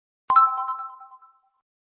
notification.mp3